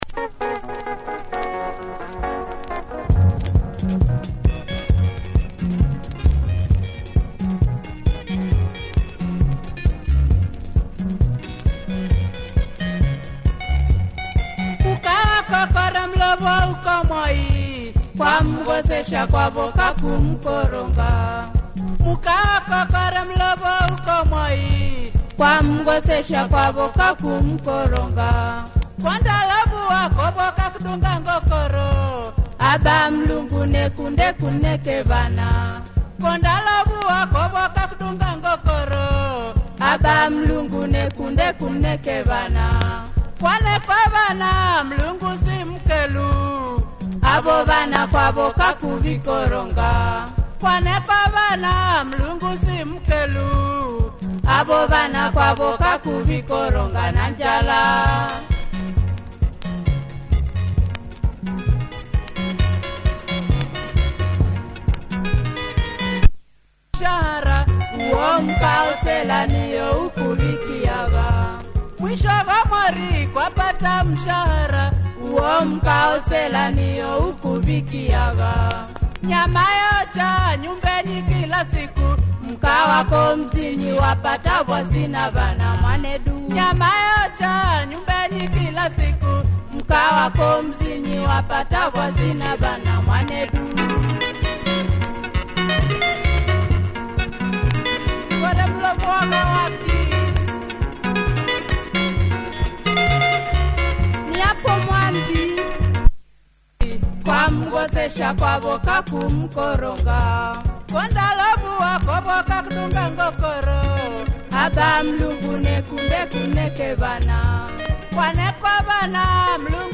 tight bass riff and good vocals, club groover